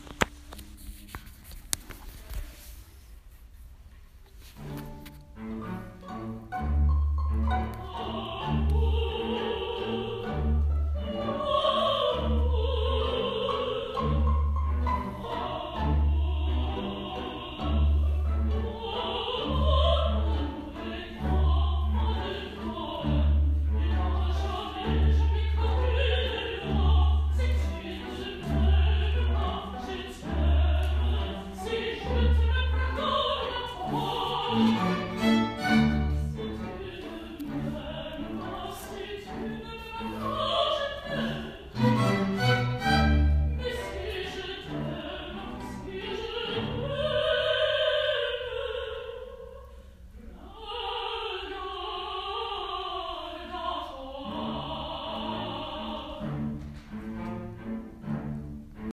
CHARLES CITY — The single violin flitters up and down a set of measures, repeating them to perfect the tone and character. Chatter fills in the background as a low thrumming.
Cases click open. More violinists start their tuning.